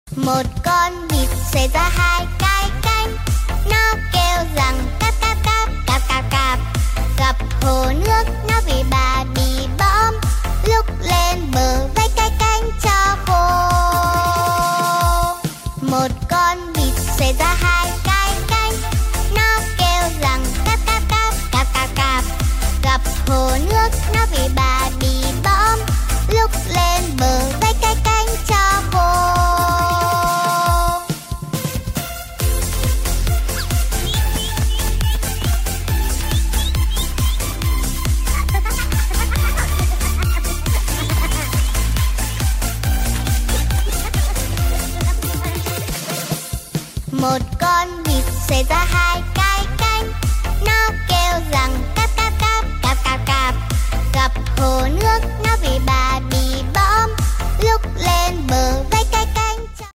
Nhạc cho bé, bài hát